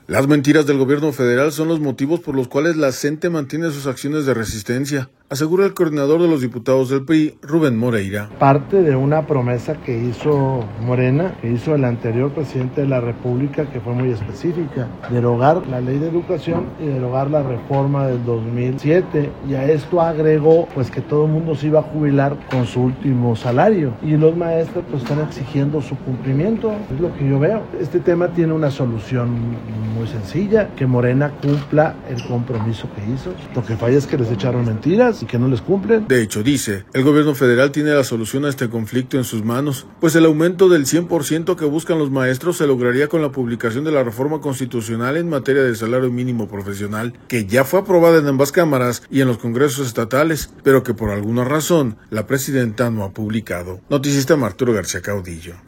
Las mentiras del Gobierno Federal son los motivos por los cuales la CNTE mantiene sus acciones de resistencia, asegura el coordinador de los diputados del PRI, Rubén Moreira.